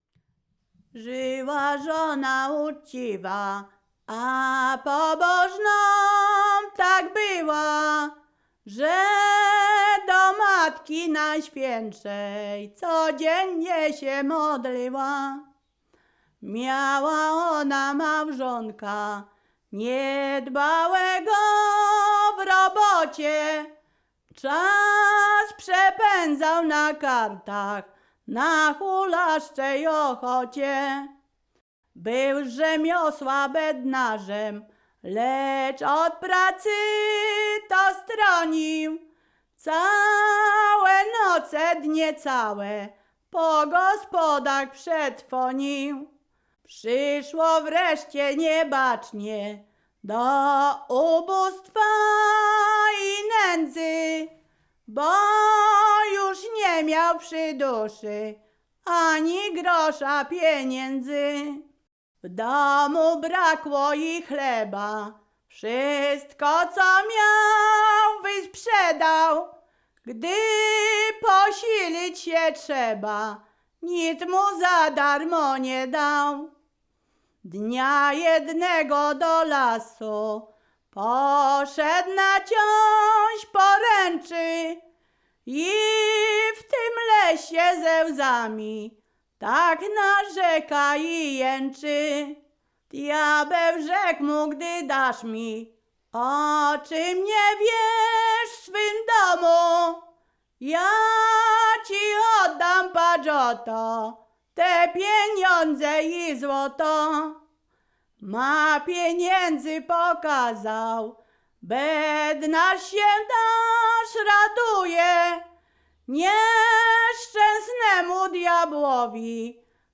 Ziemia Radomska
województwo mazowieckie, powiat przysuski, gmina Rusinów, wieś Gałki Rusinowskie
ballady dziadowskie nabożne maryjne